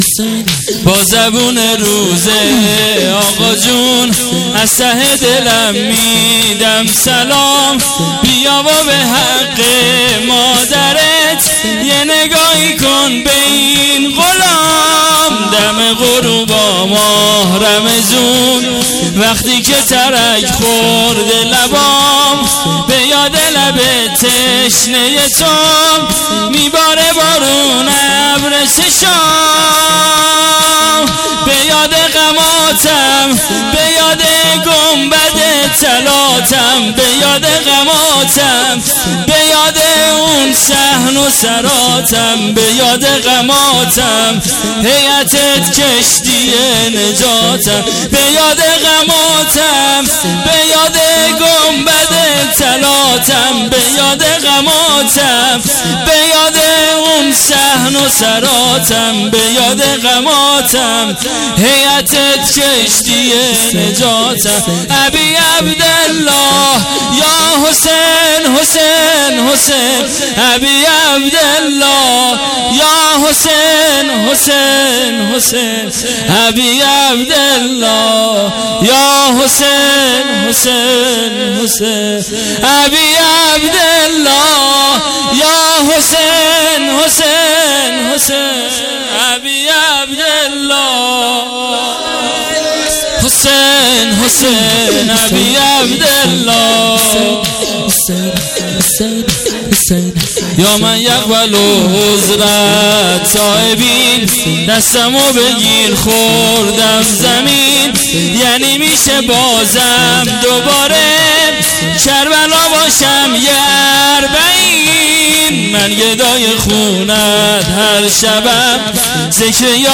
وفات حضرت خدیجه(س)99